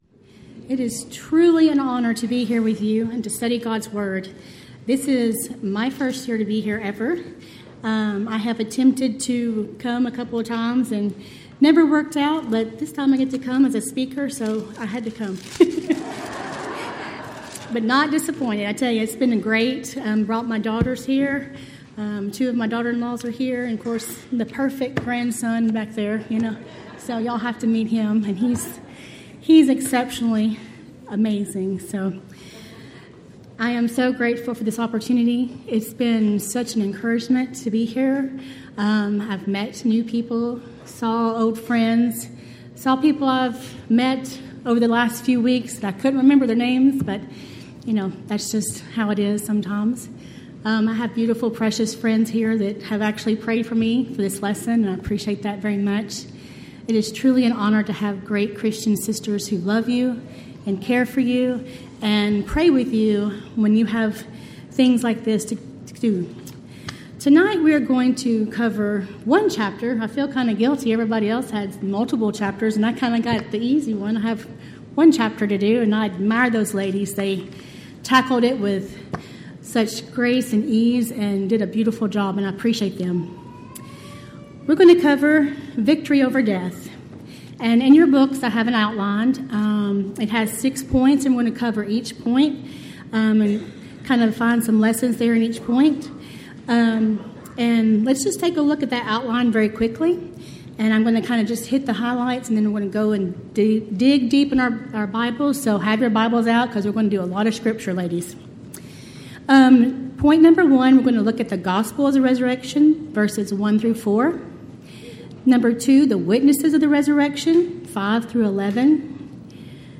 Event: 13th Annual Texas Ladies in Christ Retreat
Ladies Sessions